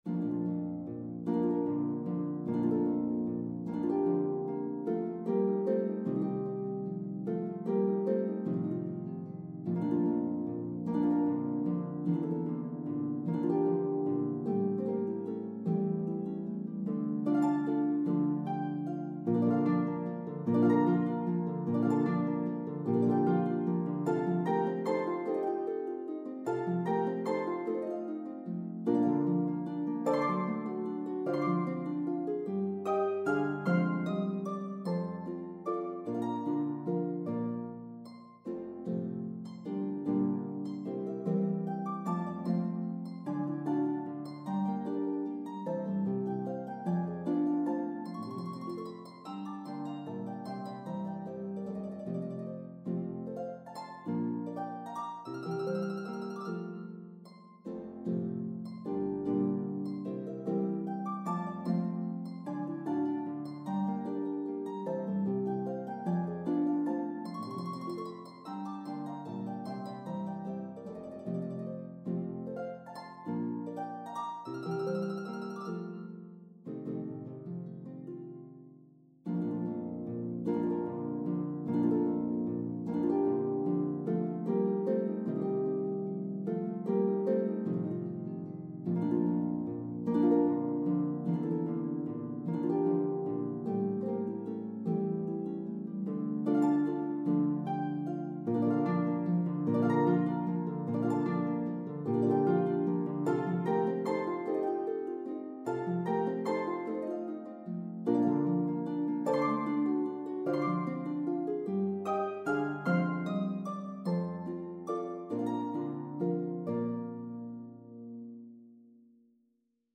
Pedal Harp Duet